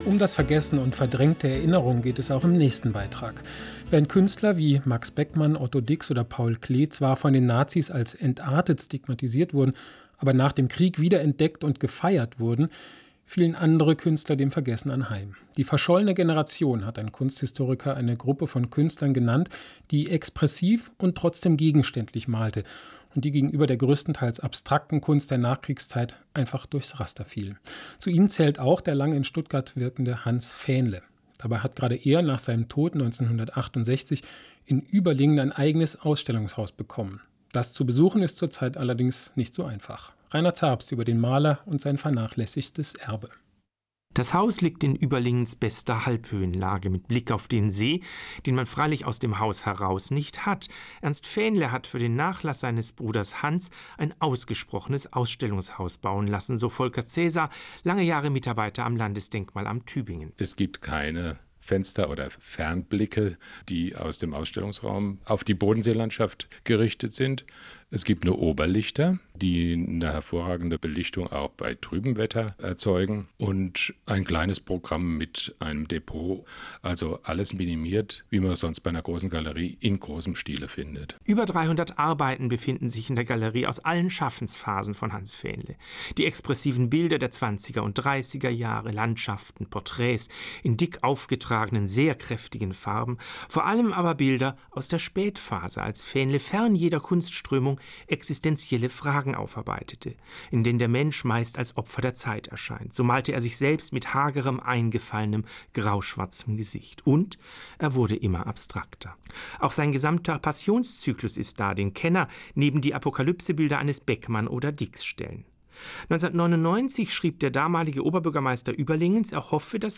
Galerie Fähnle im Radio - zum Nachhören